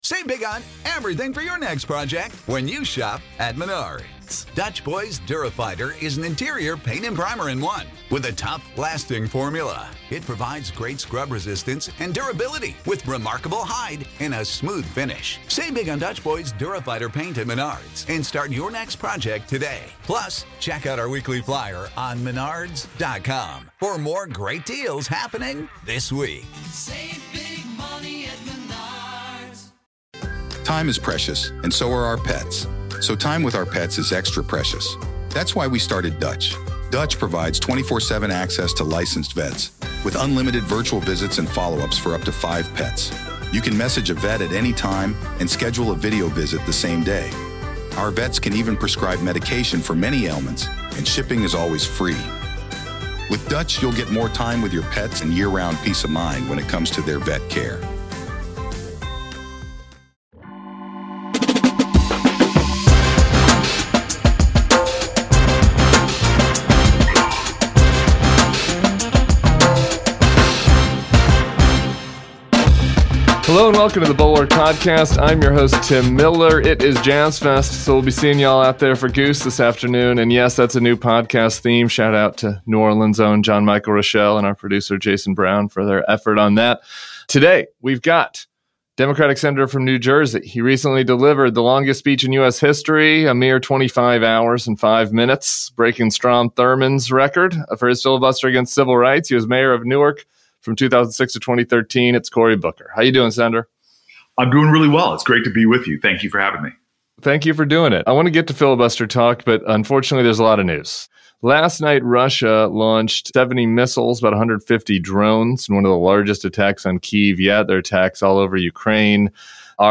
Sen. Cory Booker joins Tim Miller.